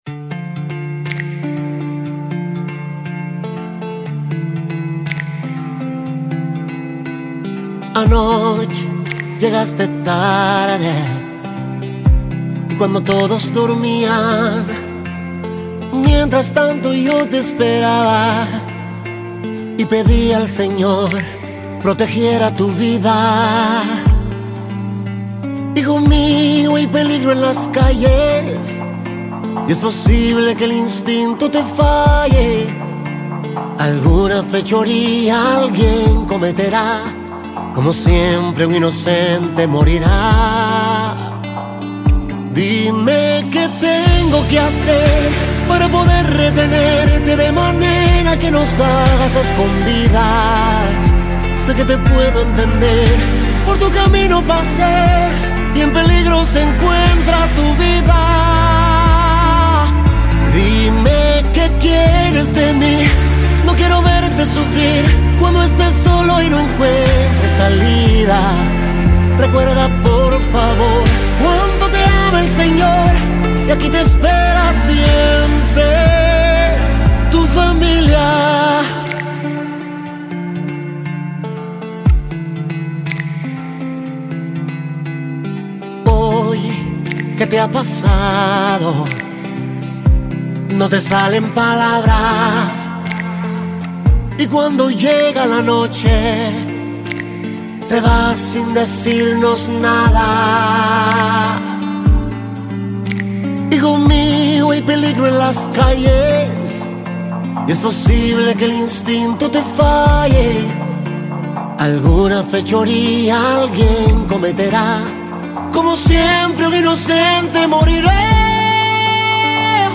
Musica Cristiana